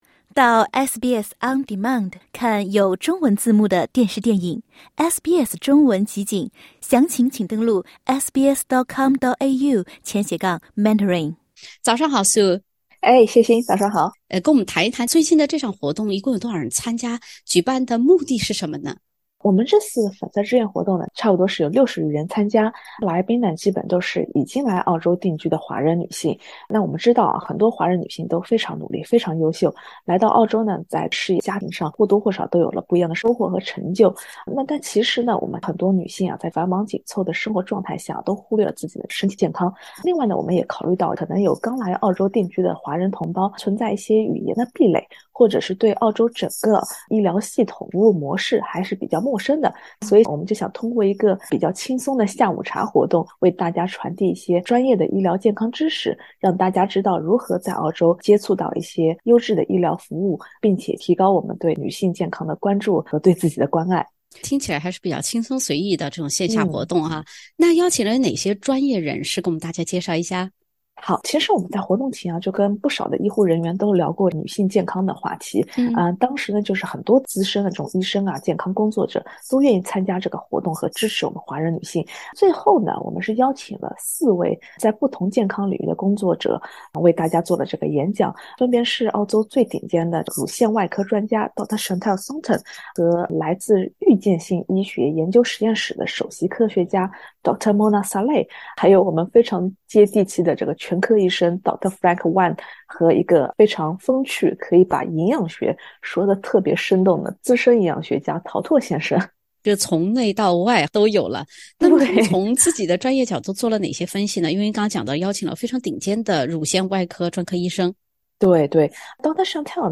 墨尔本一场以女性钟爱的“粉色”为主题的活动，邀请了多位澳洲顶尖医学专业人士，为女性朋友们普及健康知识。（点击封面图片，收听完整采访）